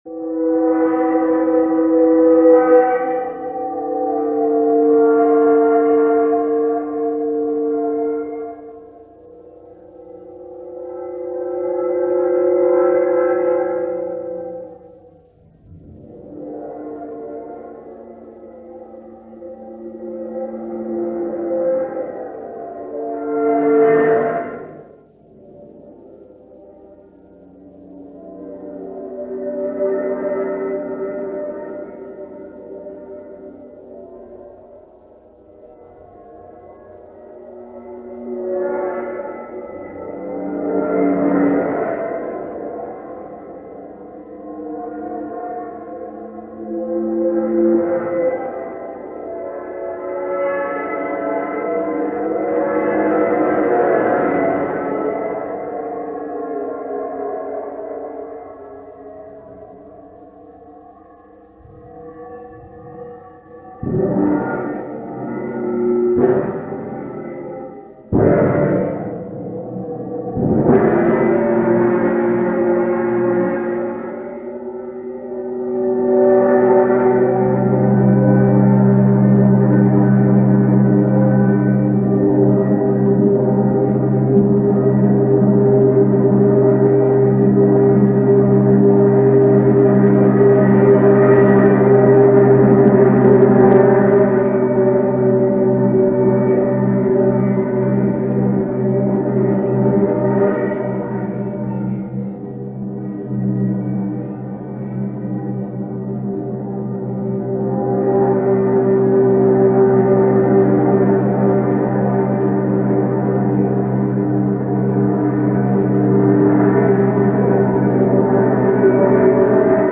An AGC was developed and inserted into the electroacoustic feedback system loop, which counteracted the error state and produced a wide range of continuous metallic feedback sounds, electronic and acoustic (Audio 1).
Audio 1 (2:11). Electroacoustic sheet metal feedback phone output.
In the electroacoustic instrument, there is an overall tendency for pitch to go “up” (glissando + accelerando), similar to sounds produced from the computational feedback system loop.